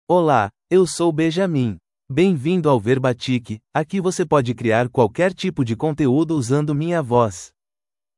MalePortuguese (Brazil)
Voice sample
Male
Convert any text to natural Portuguese speech using Benjamin's male voice.
Benjamin delivers clear pronunciation with authentic Brazil Portuguese intonation, making your content sound professionally produced.